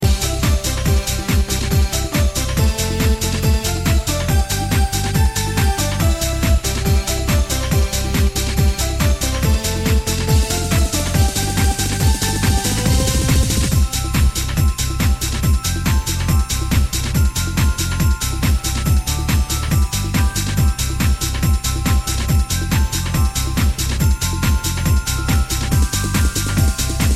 Kategorie Świąteczne